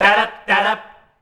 DARAB   F.wav